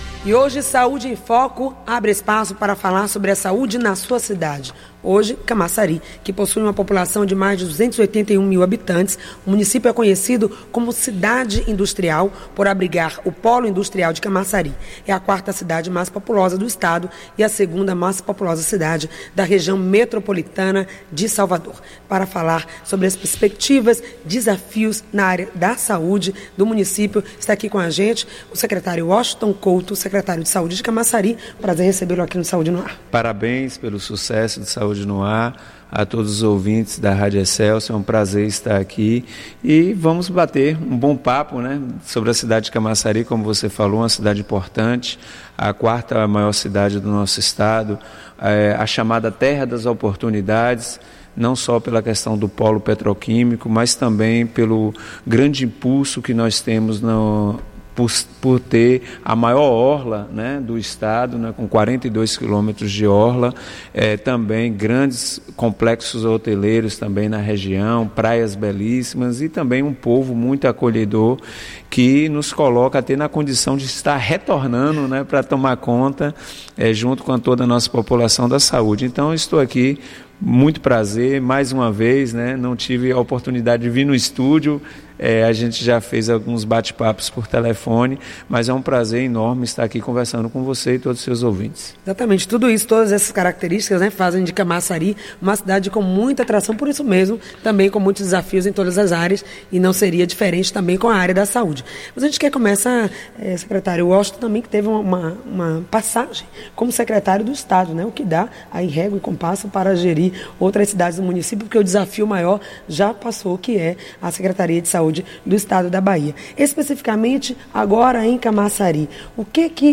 A saúde em Camaçari – Entrevista com o secretário Washington Couto
O programa intitulado “A saúde na sua cidade”, é apresentado nas últimas sextas-feiras do mês, trazendo sempre um secretário da área para falar da saúde em sua cidade.